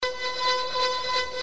描述：声音设计导致一个持续的音符适合扩散到一个八度空间；用Native Instruments Reaktor创建
标签： 电子 工业 笔记 声音设计
声道立体声